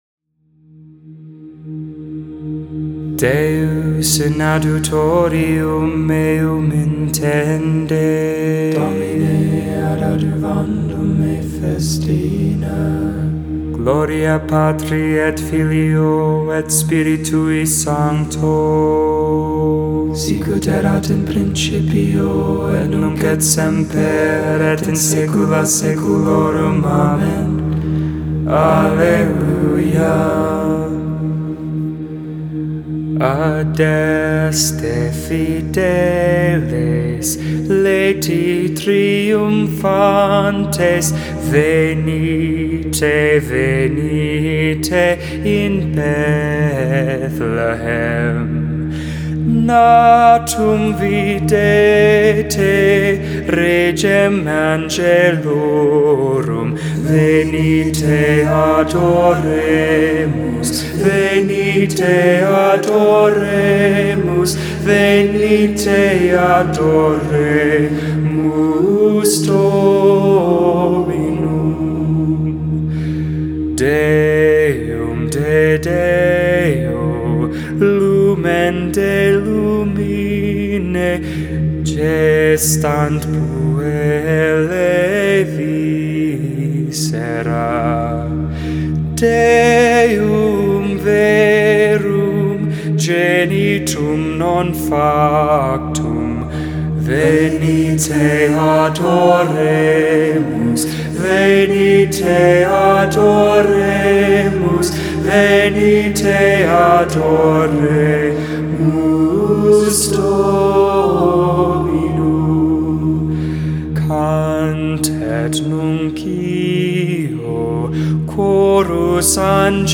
The Liturgy of the Hours: Sing the Hours 12.24.20 Christmas Eve Vespers I Dec 24 2020 | 00:15:48 1x 00:00 / 00:15:48 Subscribe Share Spotify RSS Feed Share Link Embed